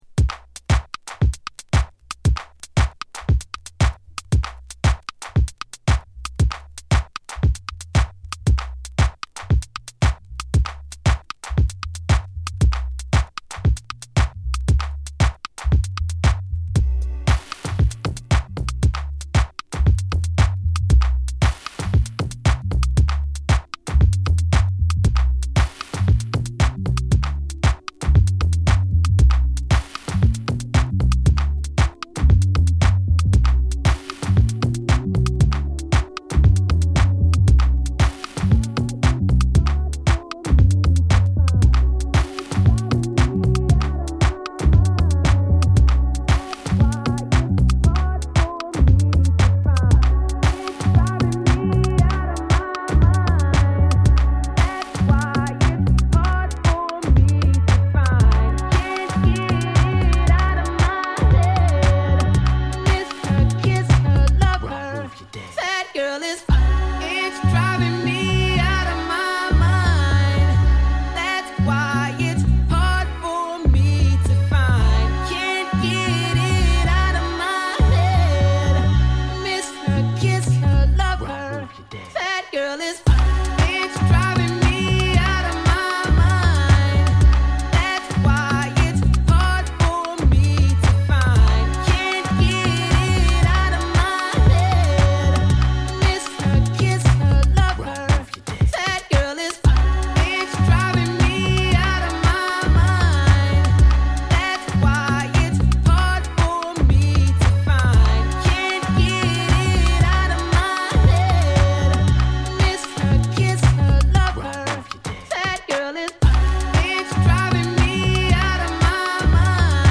同じくベースの太さが際立つオールドスクールマナーな女性ラップの掛け合いも心地よい
6/8リズムのグルーヴにピアノと女性ヴォーカルによるジャズの世界が展開される